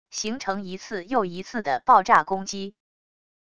形成一次又一次的爆炸攻击wav音频